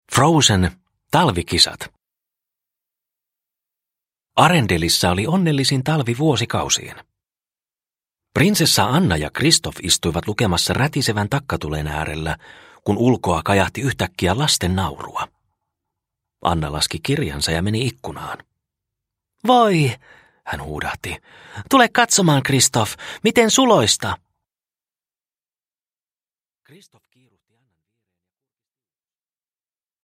Talvikisat – Ljudbok – Laddas ner